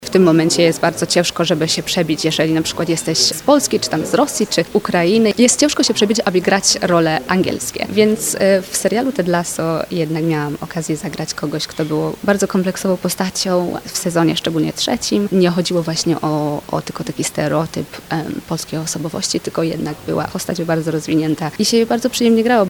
Jak powiedziała radiu RDN Nowy Sącz, udało jej się przebić szklany sufit, który mimo wszystko cały czas istnieje dla osób z Europy Wschodniej. https